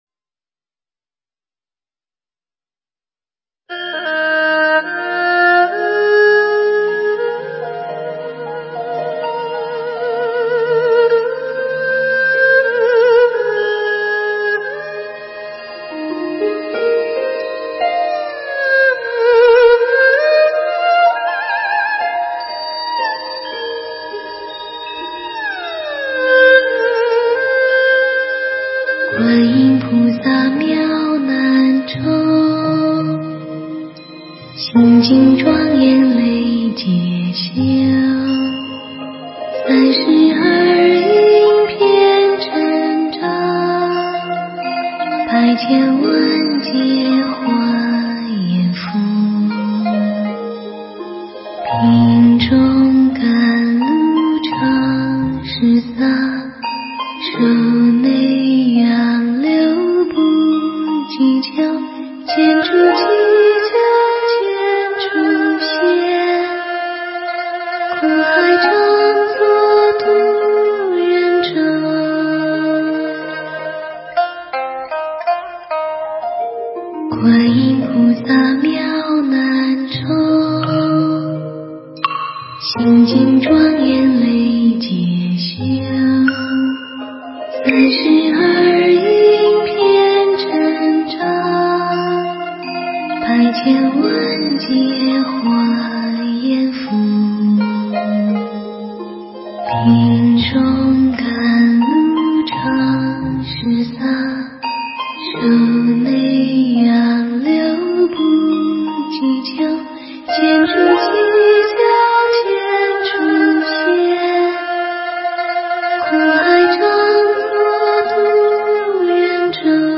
观音菩萨偈 诵经 观音菩萨偈--天籁梵音 点我： 标签: 佛音 诵经 佛教音乐 返回列表 上一篇： 南无阿弥陀佛(精进) 下一篇： 贫女的一灯 相关文章 圆觉经-10普觉菩萨 圆觉经-10普觉菩萨--未知...